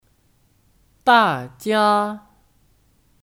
大家 (Dàjiā 大家)